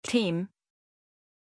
Aussprache von Tim
pronunciation-tim-zh.mp3